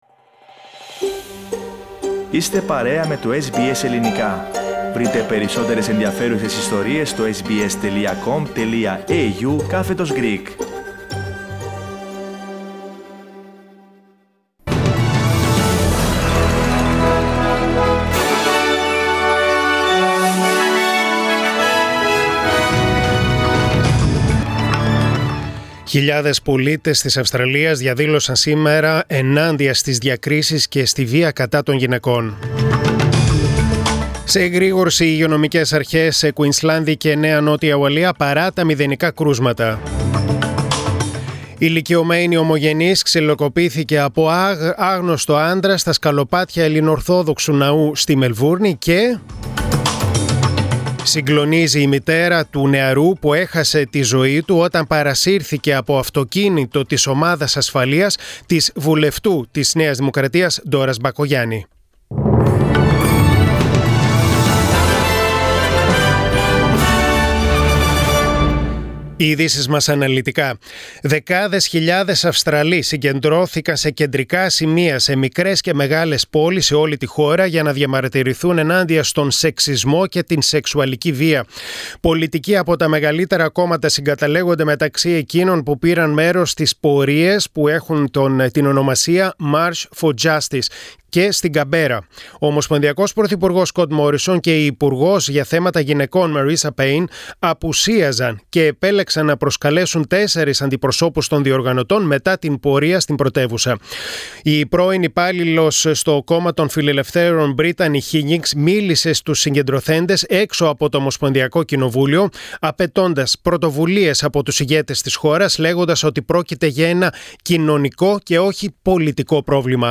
News in Greek: Monday 15.3.2021